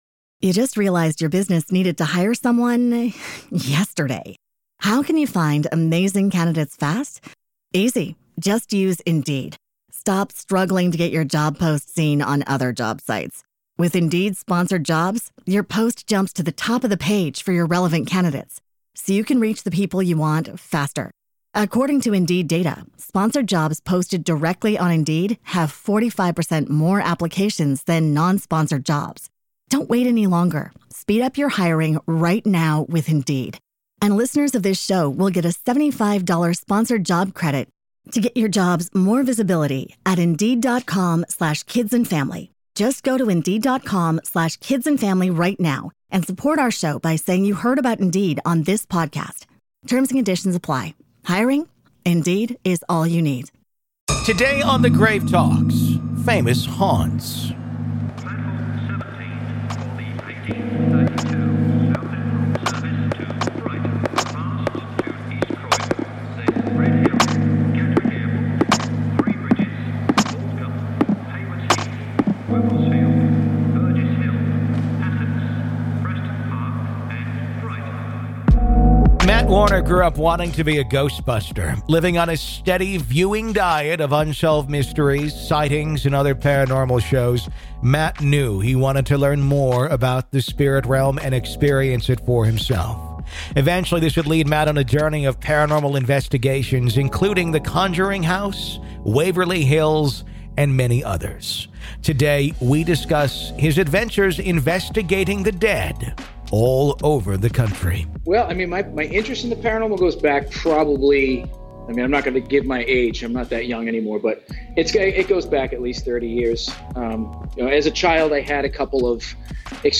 If you're enjoying our interviews and conversations about "The Dead," why not listen ad-free?